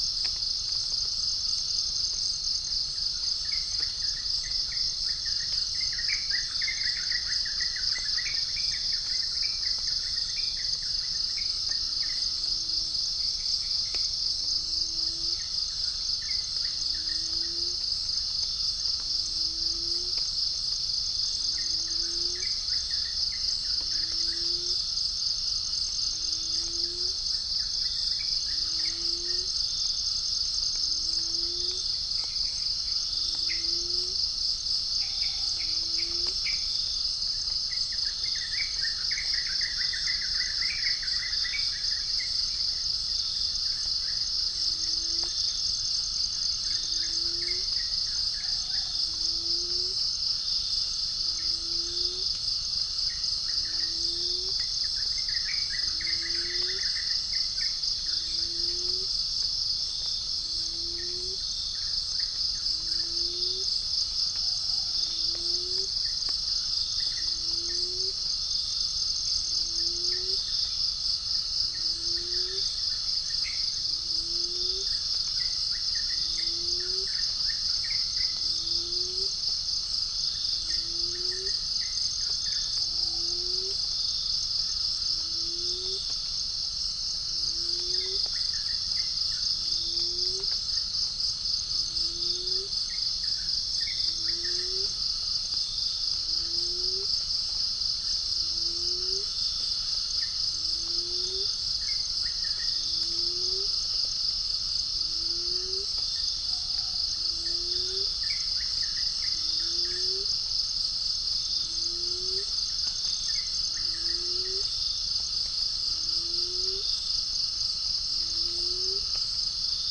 Chalcophaps indica
Gallus gallus
Pycnonotus goiavier
Prinia familiaris
0 - unknown bird